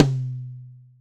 West MetroTom (17).wav